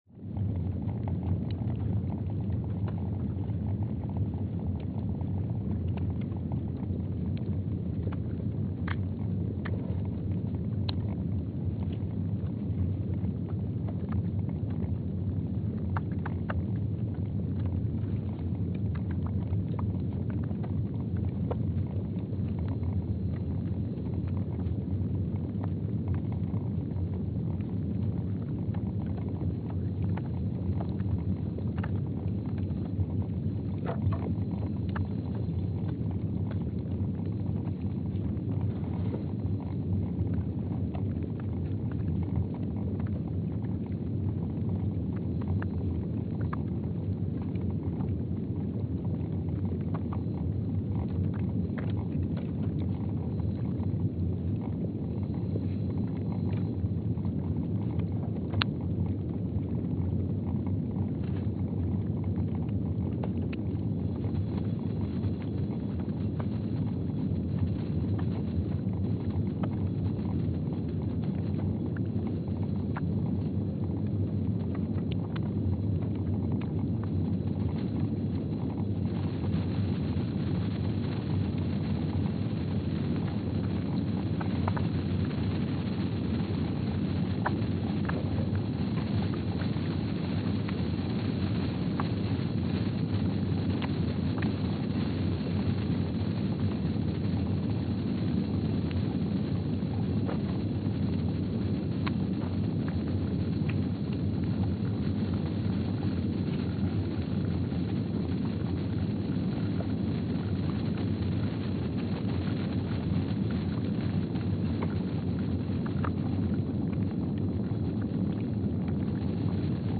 Palmer Station, Antarctica (seismic) archived on January 31, 2025
Station : PMSA (network: IRIS/USGS) at Palmer Station, Antarctica
Speedup : ×500 (transposed up about 9 octaves)
Loop duration (audio) : 05:45 (stereo)
Gain correction : 25dB
SoX post-processing : highpass -2 90 highpass -2 90